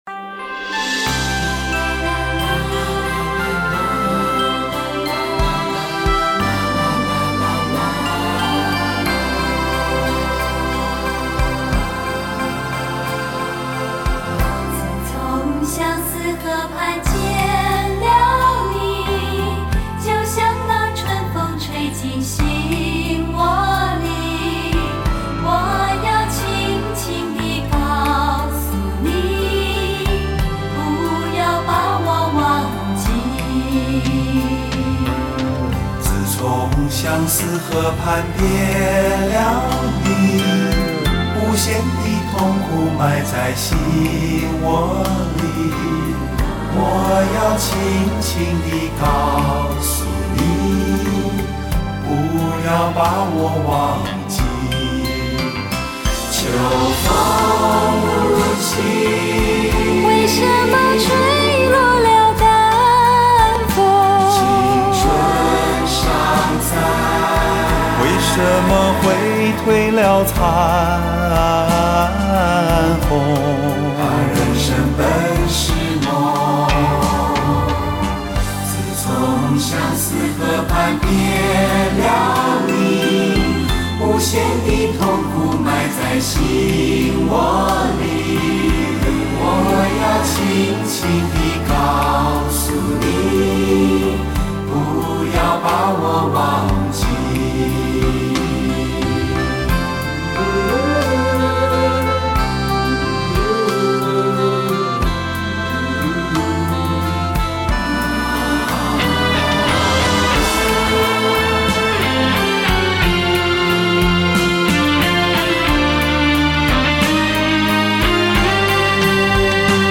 似乎經過修改後　和聲優美　唱功一流